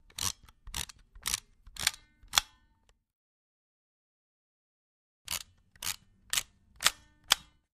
Camera Cheap, Plastic, Manual Reloading x2